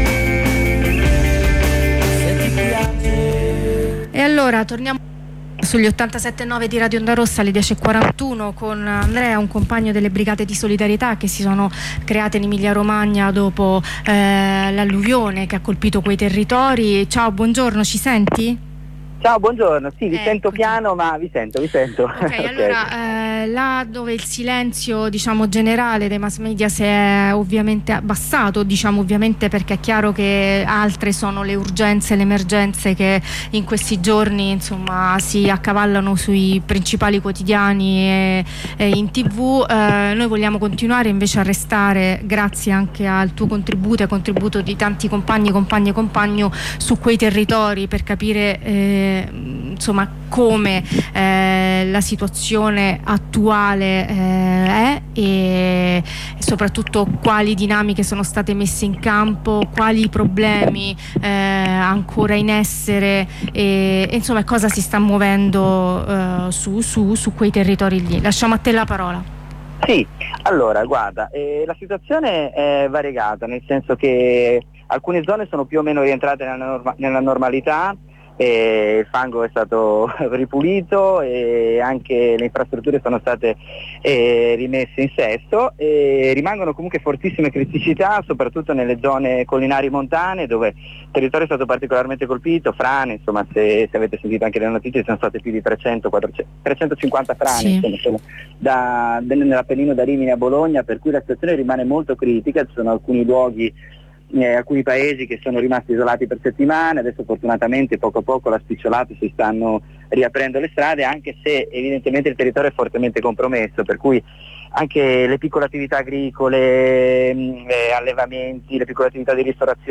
Ne parliamo con un compagno delle Brigate di Solidarietà dell'Emilia Romagna.